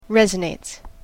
発音記号
• / ˈrɛzʌˌnets(米国英語)
• / ˈrezʌˌneɪts(英国英語)